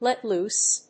lèt lóose